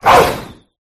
boltund_ambient.ogg